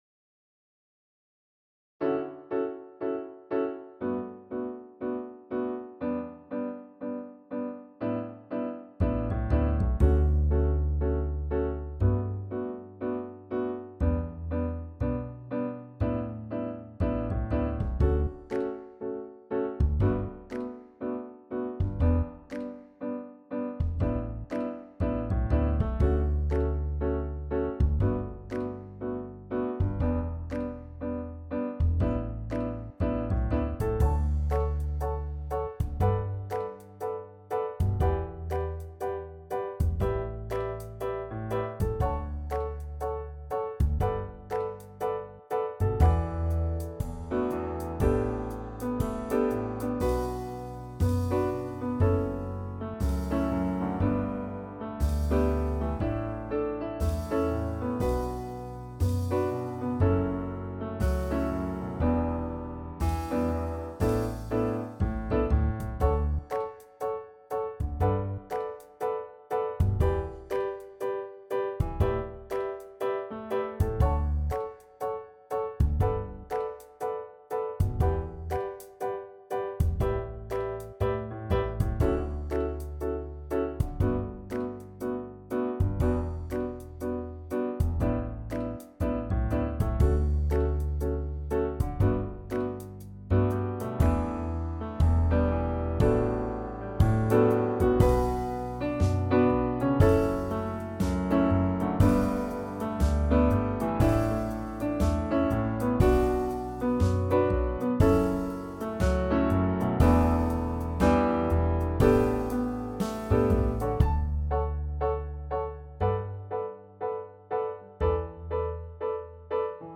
Mijn Leidsche Rijn - Orkestband
Mijn-Leidsche-Rijn-OrkestBand.m4a